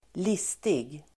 Uttal: [²l'is:tig]